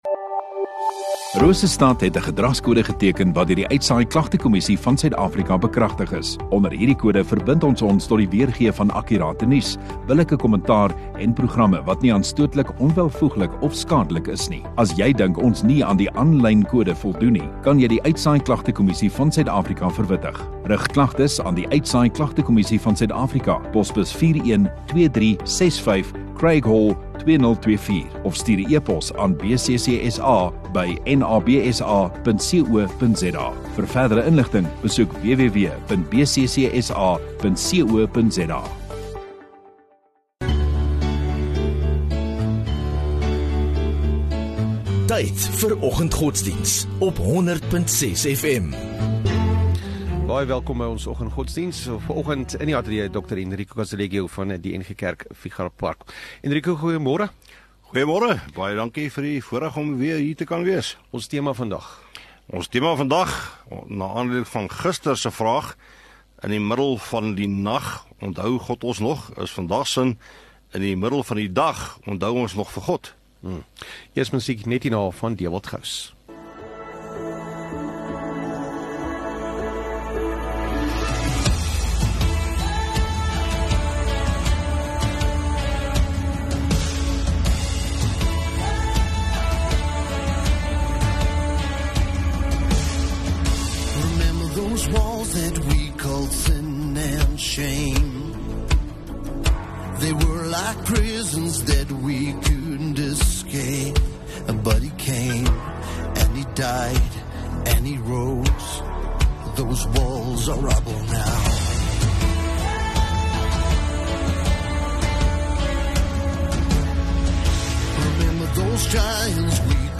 23 Jul Dinsdag Oggenddiens